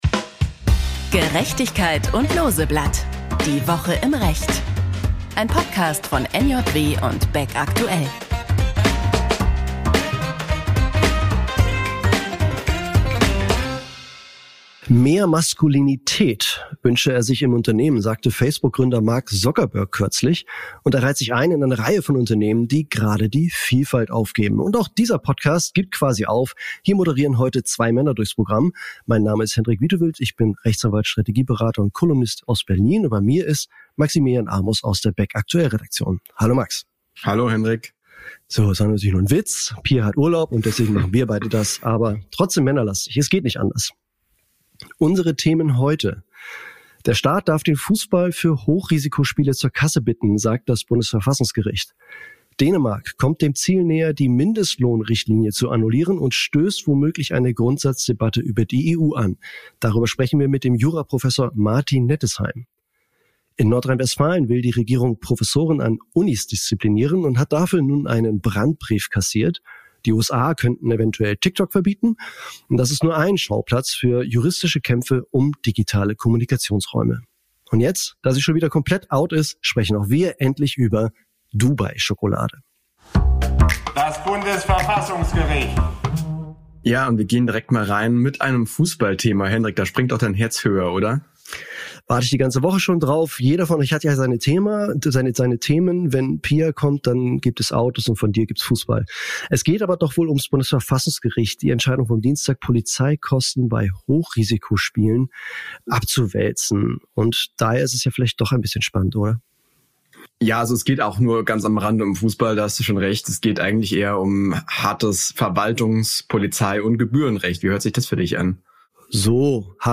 Dennoch ist er einer der bekanntesten Aktivisten für Behinderten-Rechte in Deutschland. Im Gespräch zerlegt er die Vorstellung einer vermeintlich gesunden, nicht-behinderten Gesellschaft.